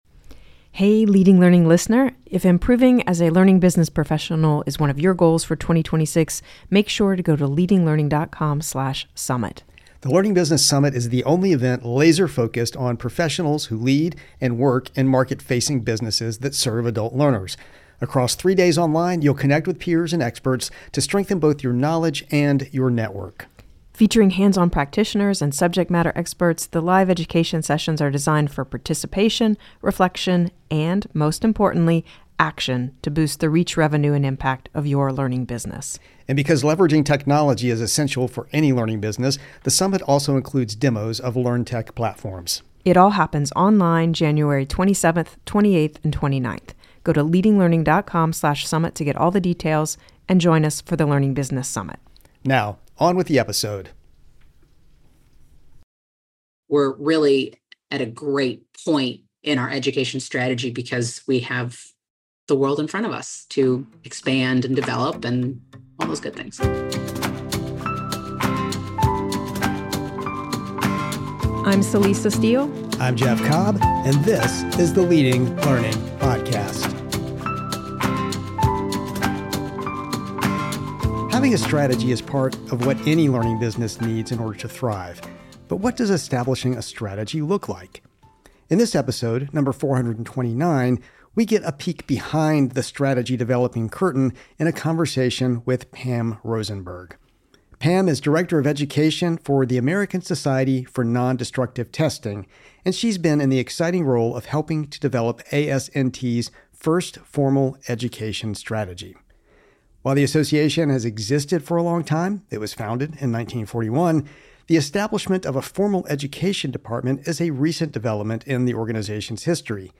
But what does establishing a strategy look like? In this episode, number 429, we get a peek behind the strategy-developing curtain in a conversation.